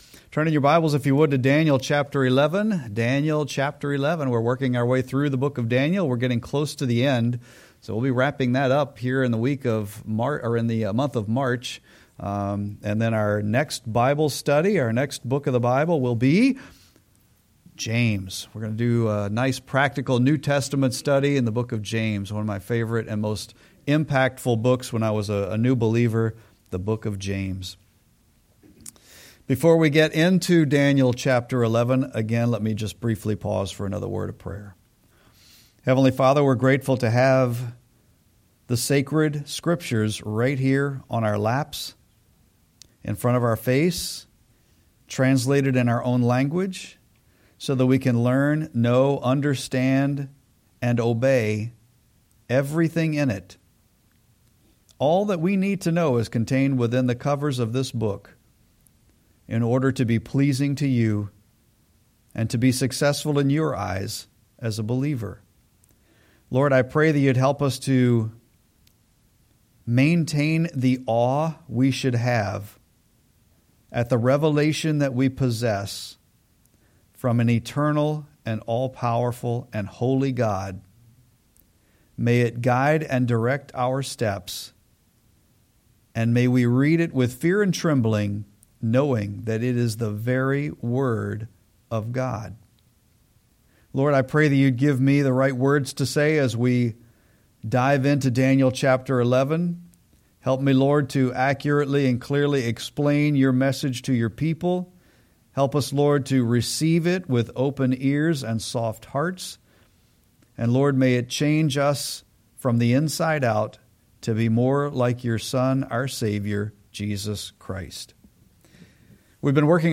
Sermon-3-9-25.mp3